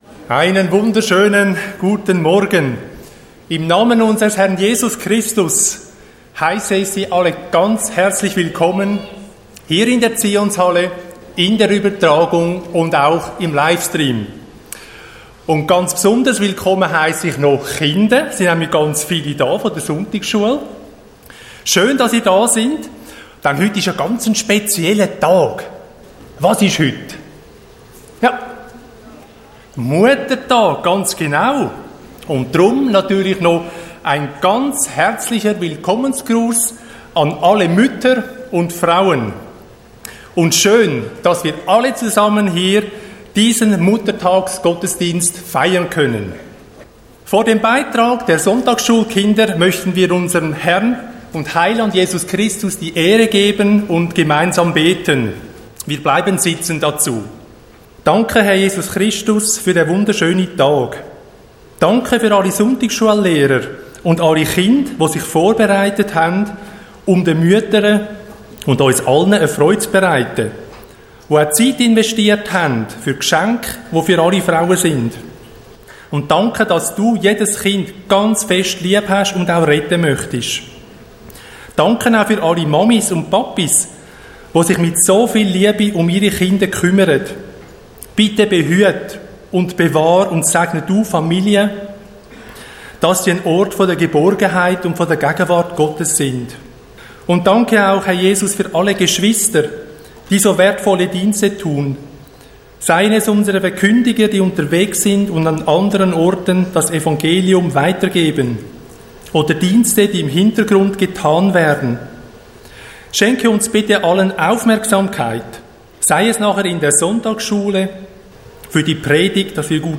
Einleitungen Gottesdienst